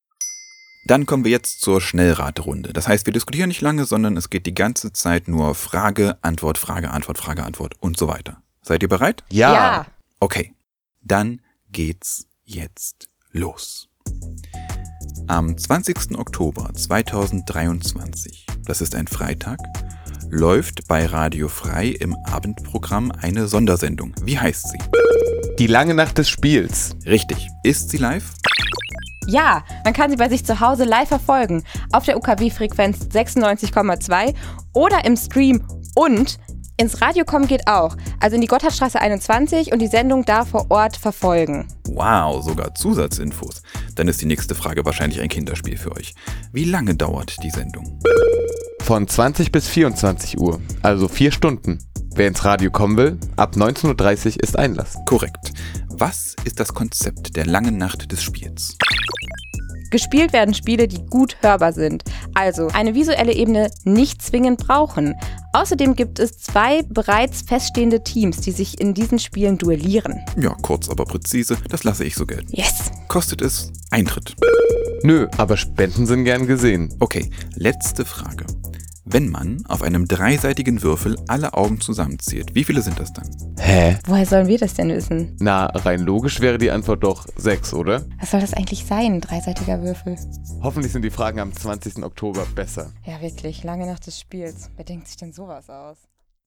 Trailer